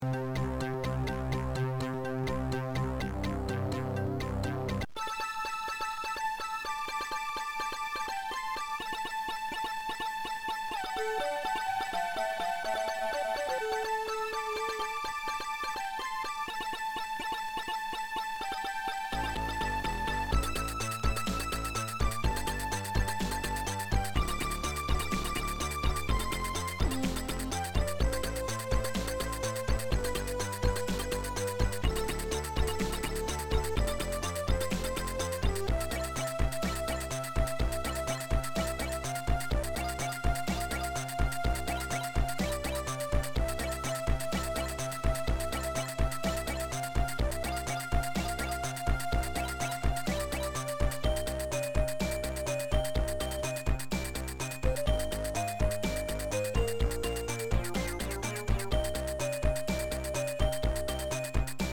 File 3 - This is recording from the zz9000ax with the three cable connector in the opposite direction on the zz9000. Notice that it seems to play fine.